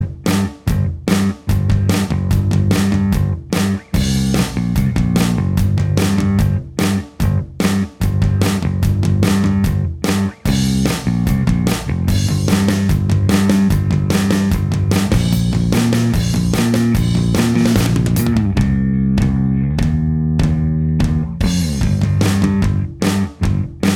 Minus All Guitars Punk 3:59 Buy £1.50